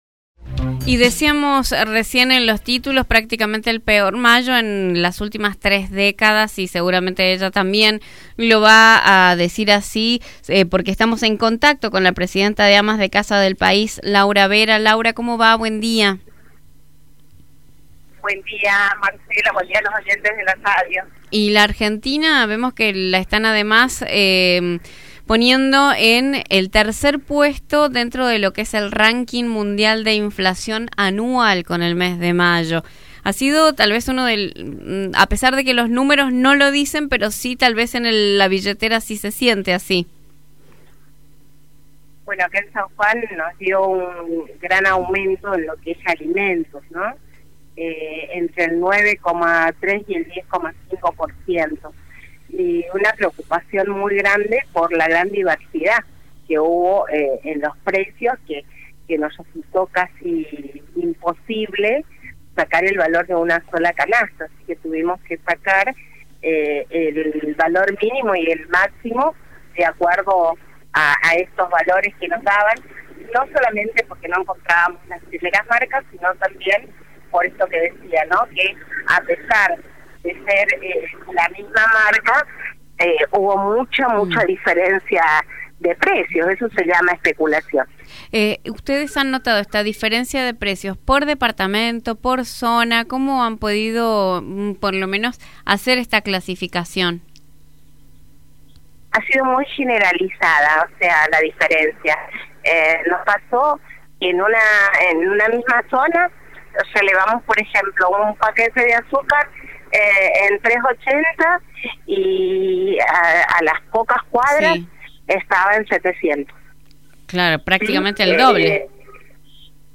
Escucha la nota completa: